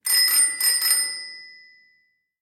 cycle.mp3